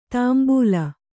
♪ tāmbūla